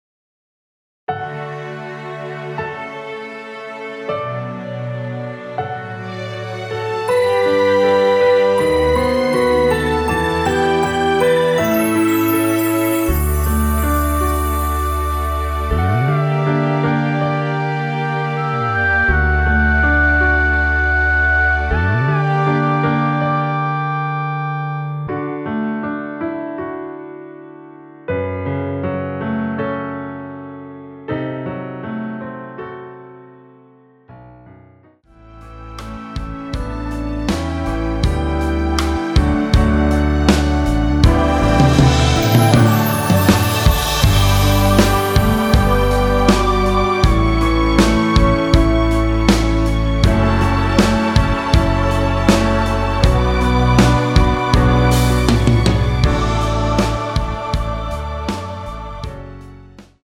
원키에서(-1)내린 MR입니다.
D
앞부분30초, 뒷부분30초씩 편집해서 올려 드리고 있습니다.
중간에 음이 끈어지고 다시 나오는 이유는